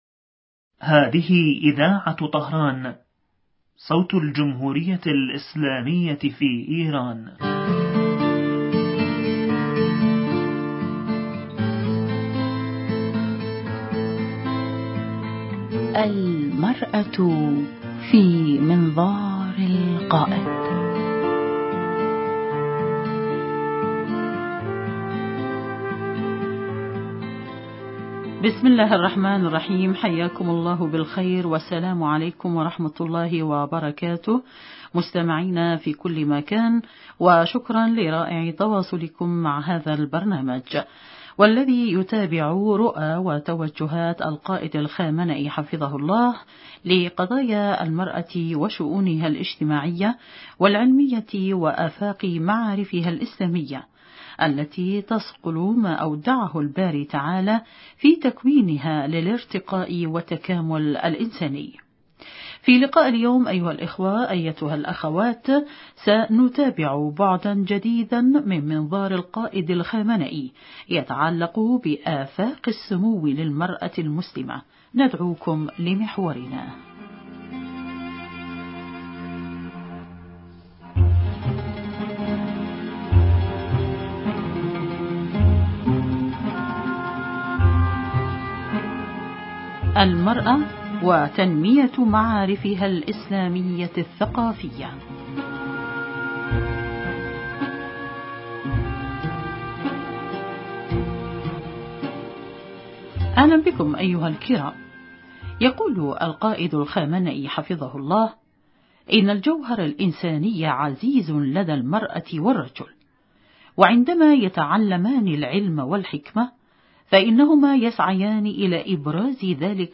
ضيفة البرنامج عبر الهاتف